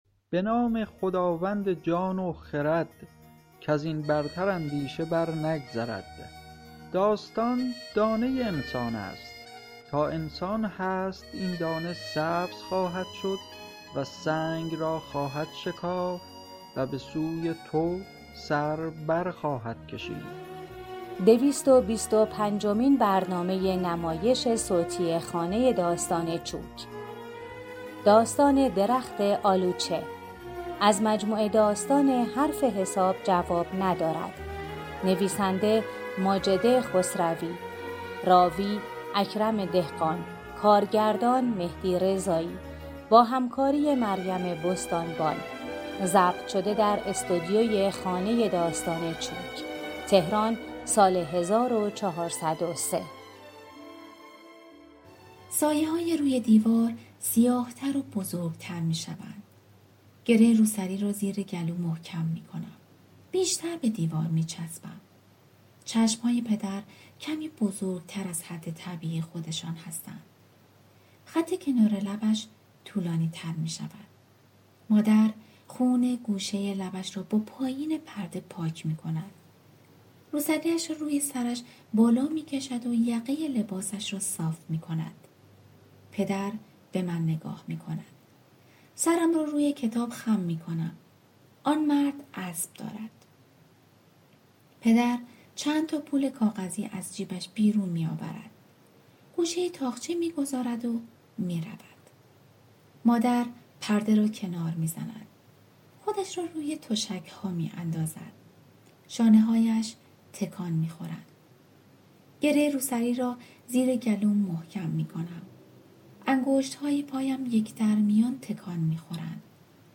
در کتاب صوتی درخت آلوچه نوشته‌ی ماجده خسروی، روایت قصه مدام میان زمان حال و گذشته، و موقعیت‌های مختلف، حال خوب و بد و هزاران چیز دیگر تاب می‌خورد و دائم در حال تغییر است.